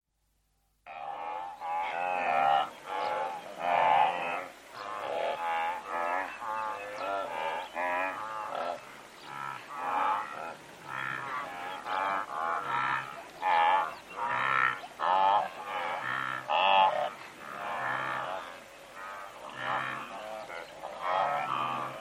Записи сделаны в дикой природе и передают атмосферу саванны.
Стадо Голубых Гну невероятно болтливые